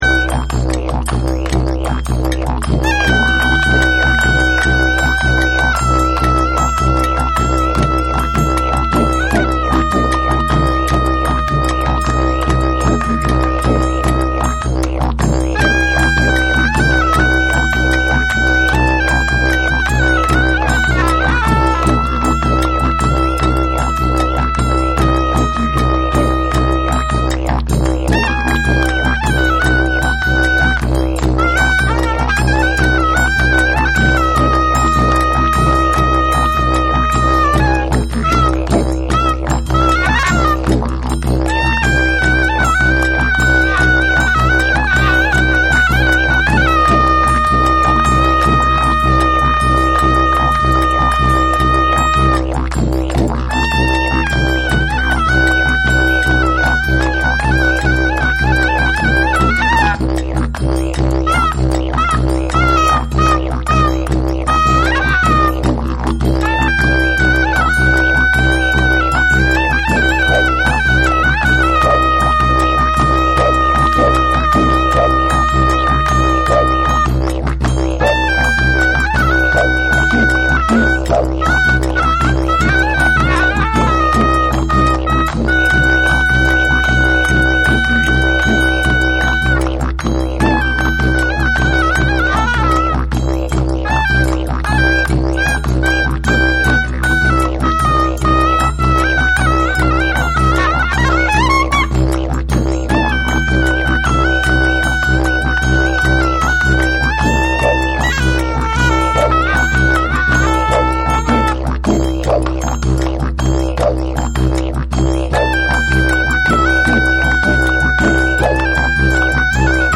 ディジュリドゥで繰り広げられる壮大なサウンドを披露する1(SAMPLE 1)。
JAPANESE / ORGANIC GROOVE